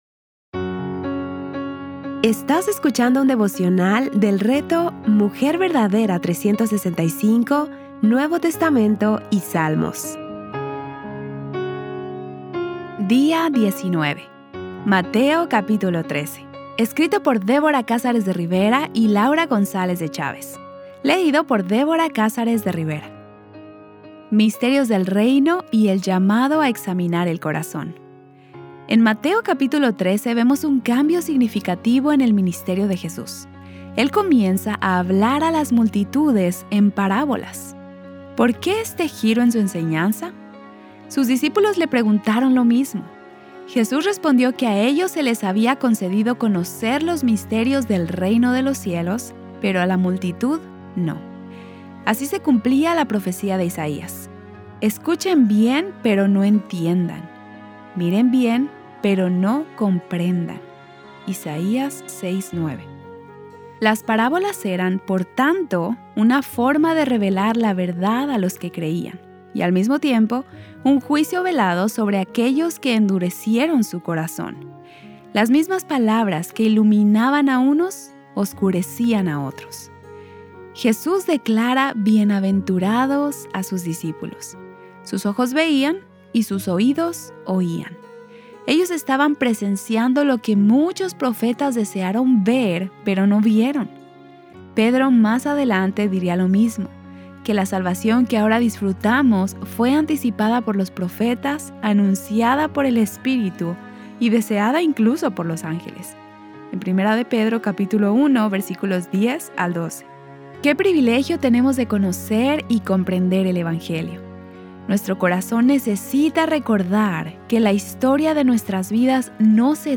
Series:  Mateo y Salmos | Temas: Lectura Bíblica